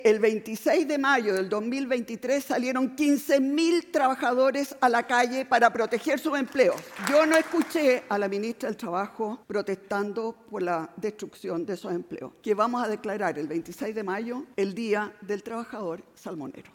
De esta manera, los aspirantes a La Moneda se reunieron en la región de Los Lagos, específicamente en el Teatro del Lago, en Frutillar, donde se está desarrollando el Salmón summit 2025.